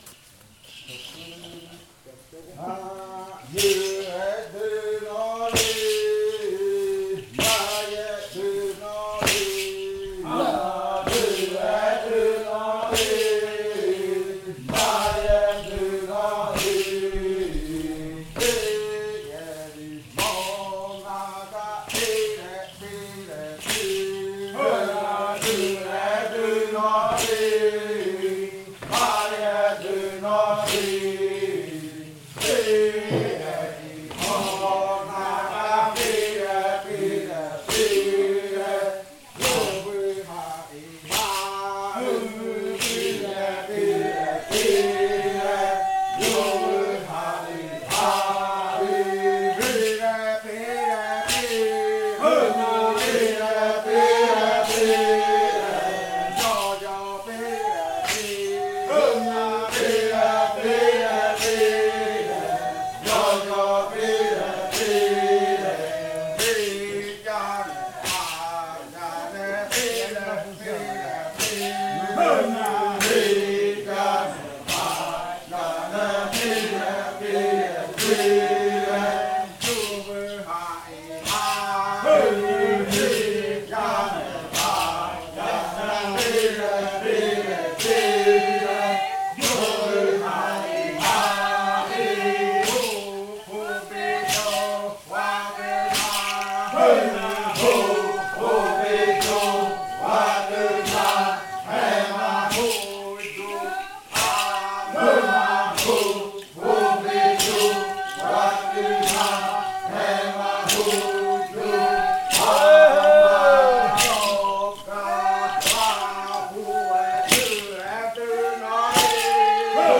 Canto de la variante muruikɨ
con el grupo de cantores bailando en Nokaido.
with the group of singers dancing in Nokaido. This song is part of the collection of songs from the yuakɨ murui-muina ritual (fruit ritual) of the Murui people, a collection that was compiled by the Kaɨ Komuiya Uai Dance Group with the support of a solidarity outreach project of the Amazonia campus of UNAL.